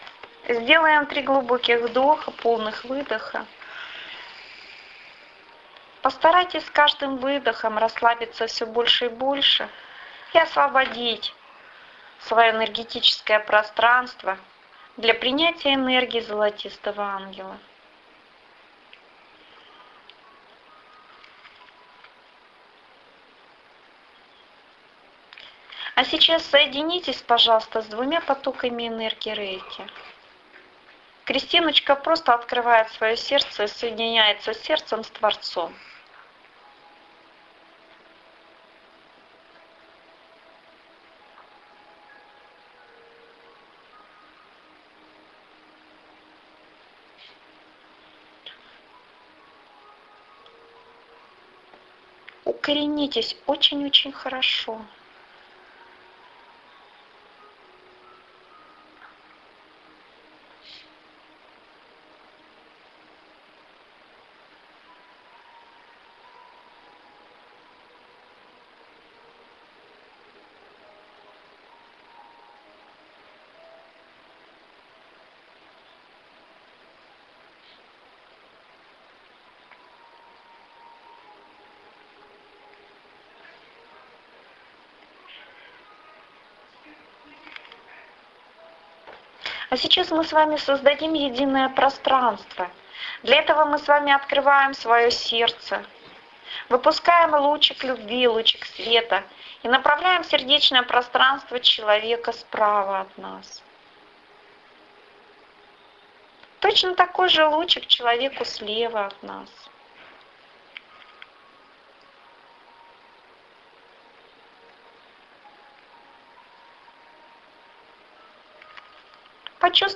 Венчание с Золотистым Ангелом Аудио медитация Мои приветствия всем вам, идущим по пути своей жизни.